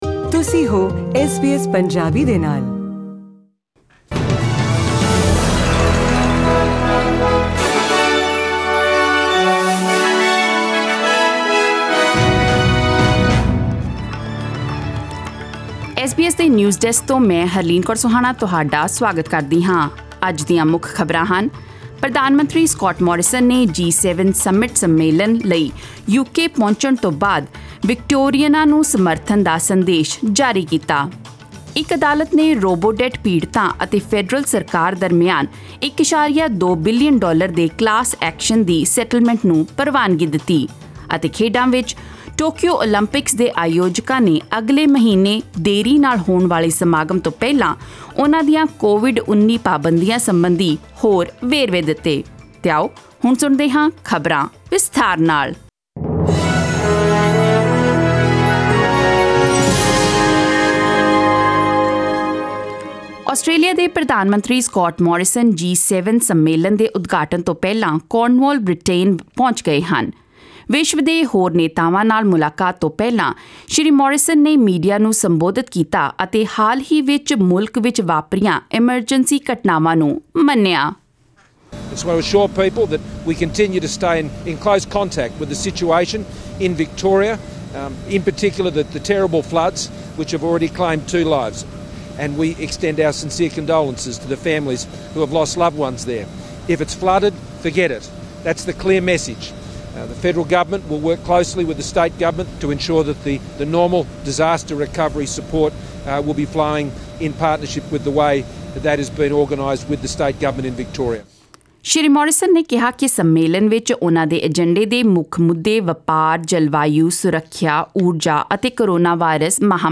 SBS Punjabi Australia News: Friday 11 June 2021